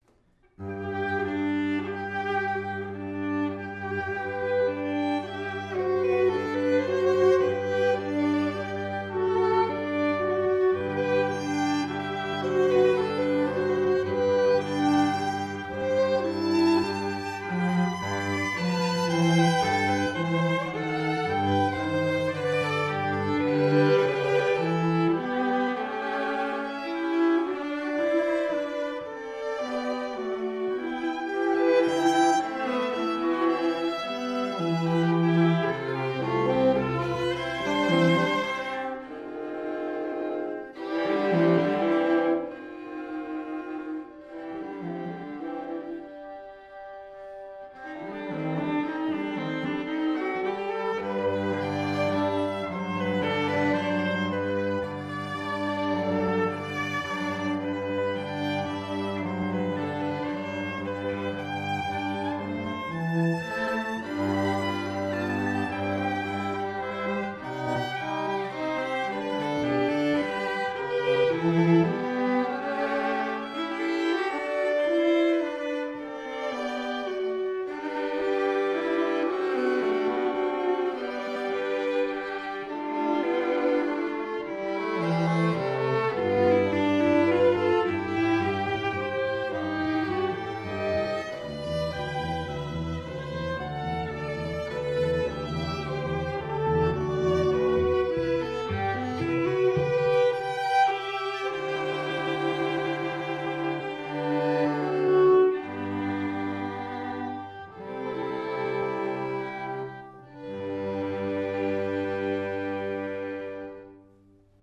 Soundbite
Violin, Viola and Cello